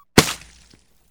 Slime.wav